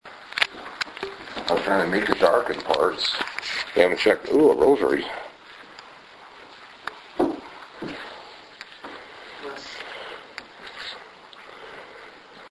8:47 p.m. – Captured in the Taille de Noyer House upstairs bedroom with the fireplace and a rosary
Oh a rosary” then afterwards you will hear the disembodied voice.
Class C EVP that is “Unintelligible”